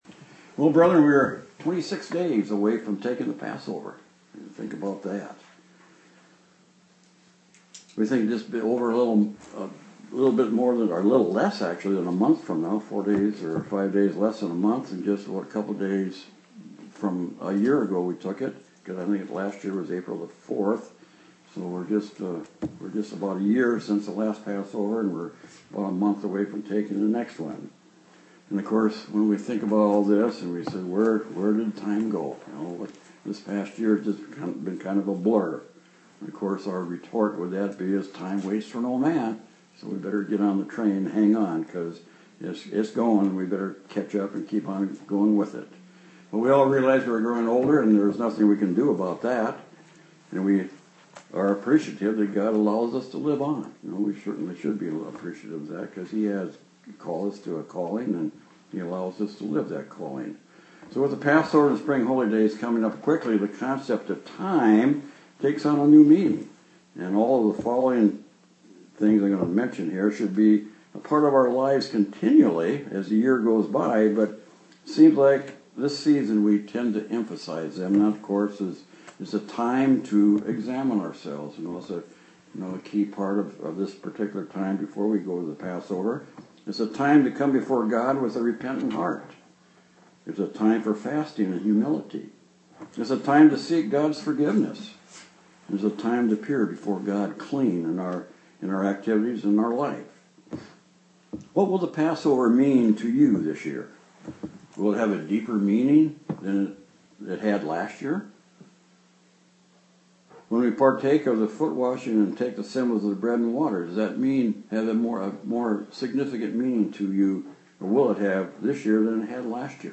Given in Austin, TX
UCG Sermon Studying the bible?